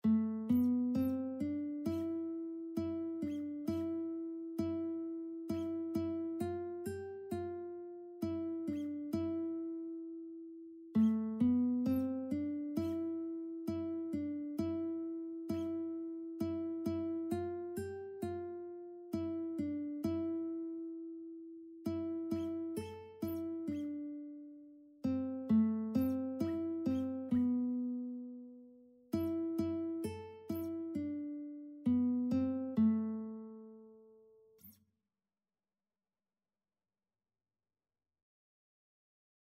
A minor (Sounding Pitch) (View more A minor Music for Lead Sheets )
4/4 (View more 4/4 Music)
Classical (View more Classical Lead Sheets Music)